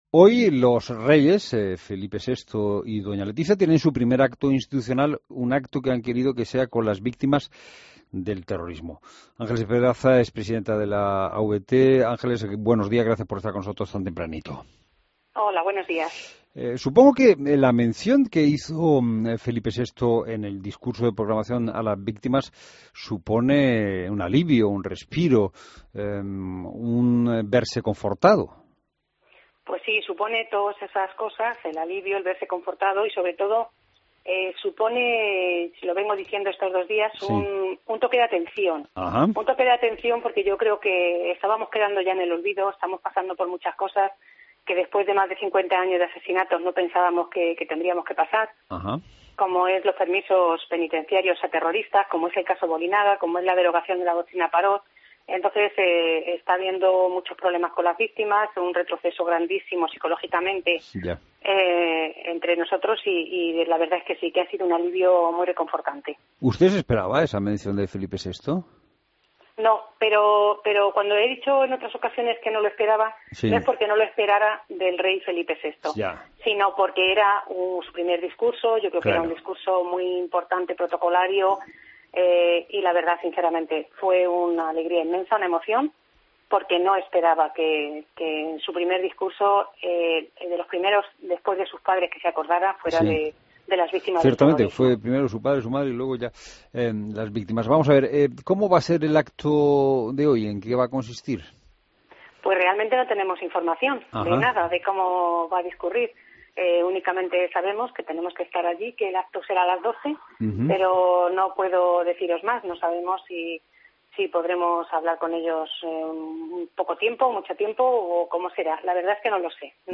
Entrevista a Ángeles Pedraza, presidenta de la AVT